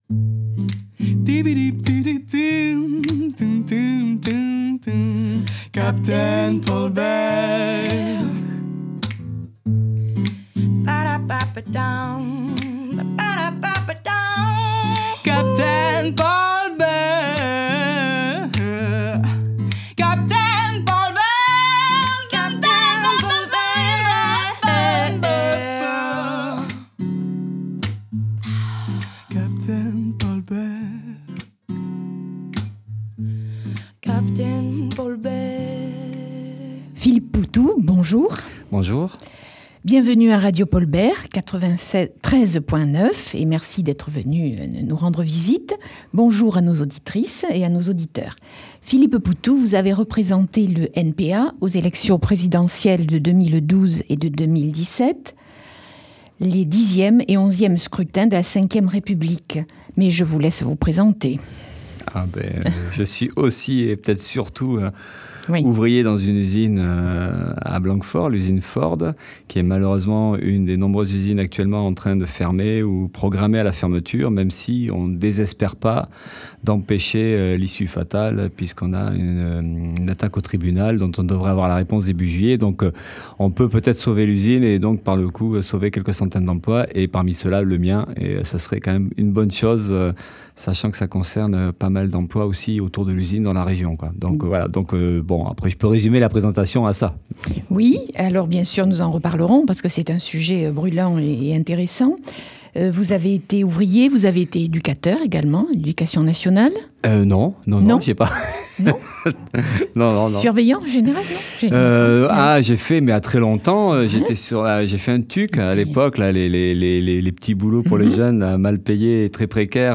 Interview de Philippe Poutou
Dans cette longue interview diffusée sur les ondes de la Radio Paul Bert, Poutou évoque plusieurs points importants : — le Nouveau Parti anticapitaliste, ses principes et son fonctionnement — l’élection présidentielle française de 2012 et 2017 — son combat syndical pour le maintien de l’activité sur le site de l’usine automobile Ford à Blanquefort — la campagne électorale en vue de l’élection municipale de Bordeaux en 2020.